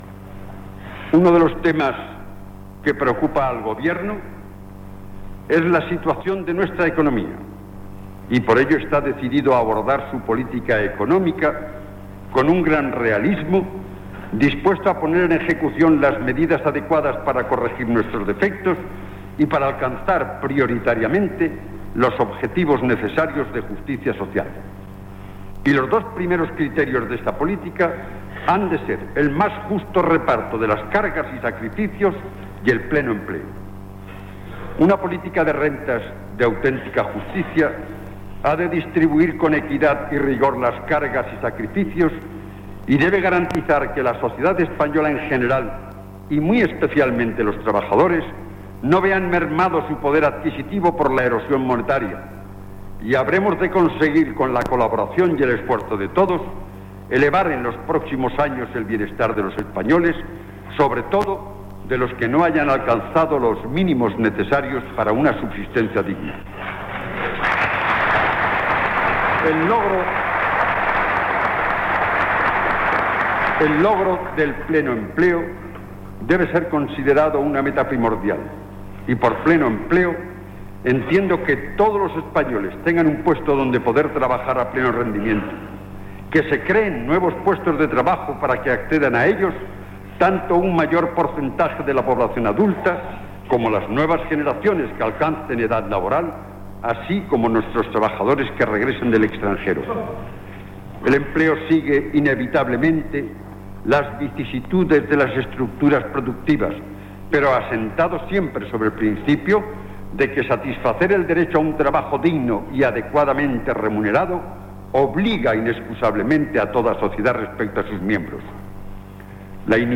ab512b51335aba00b04282dfce1c4d2709f57775.mp3 Títol Radio Nacional de España Emissora Radio Nacional de España Barcelona Cadena RNE Titularitat Pública estatal Descripció Transmissió del discurs del president del govern Carlos Arias Navarro des de les Cortes de Madrid. Presenta el seu programa de govern i parla d'economia, sindicalisme i comerç.